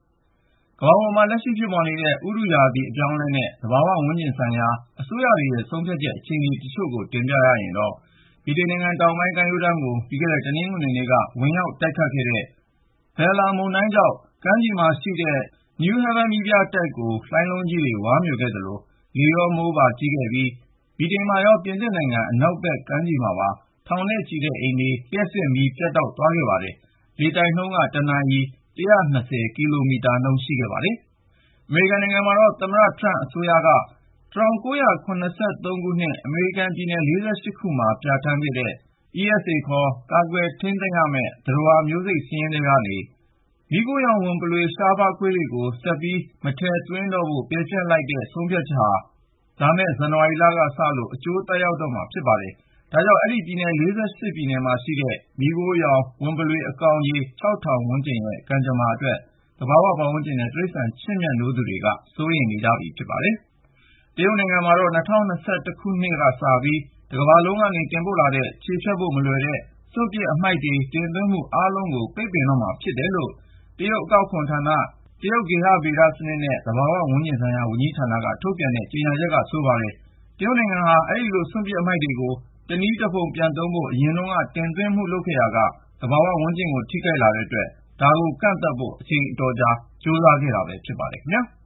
သဘာ၀ ဝန်းကျင်ဆိုင်ရာ သတင်းတိုထွာ